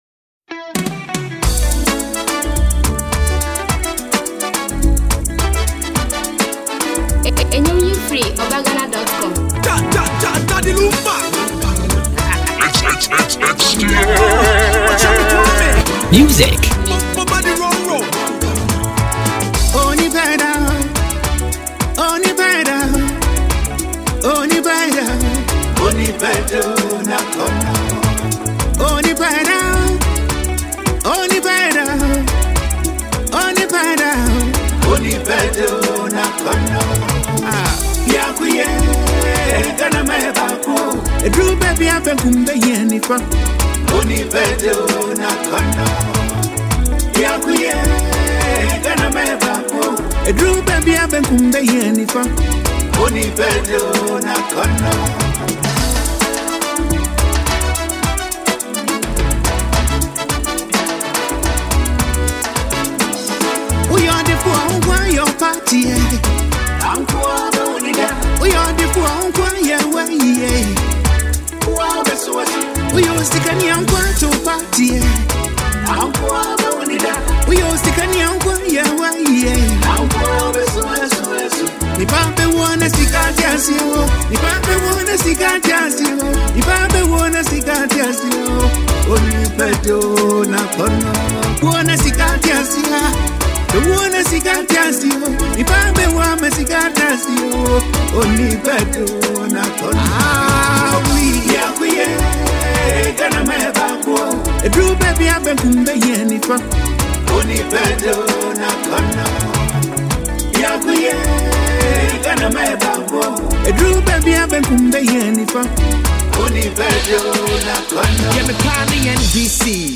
highlife song